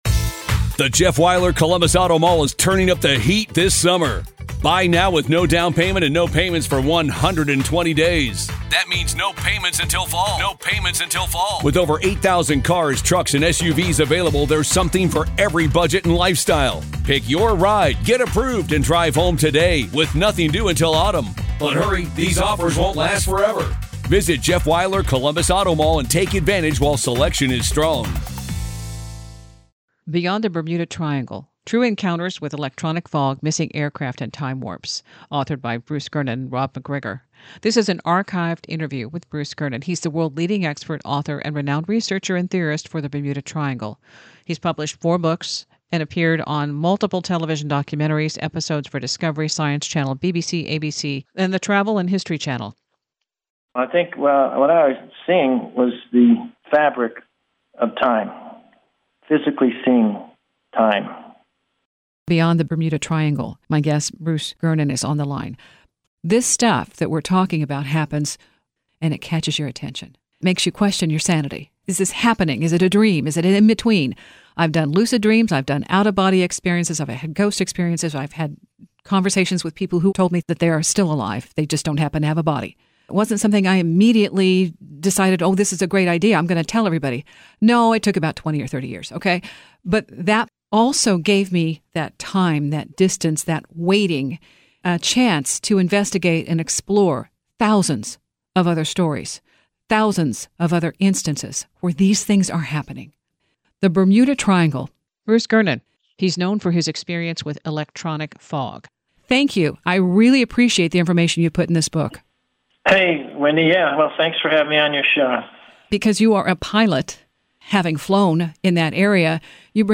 - Arthur C. Clarke Updated archive interview - copyright music removed.